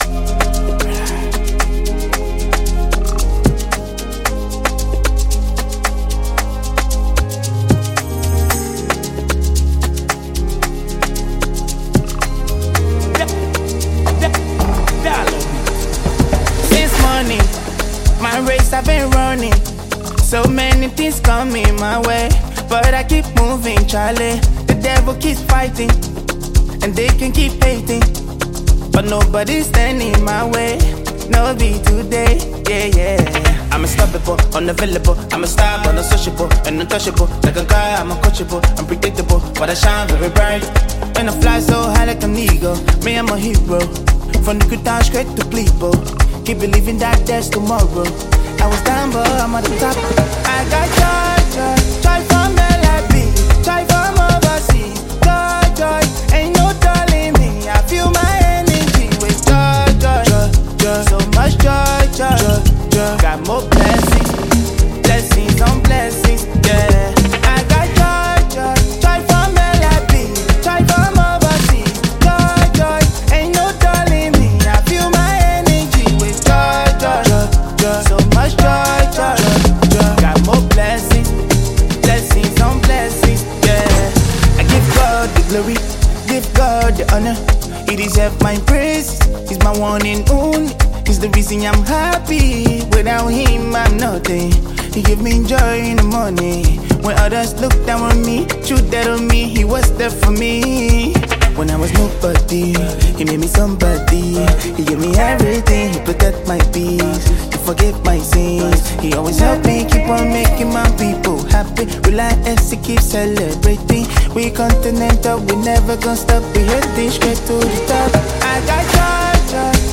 Categories: Afro-Beats,Afro-PopLiberian Music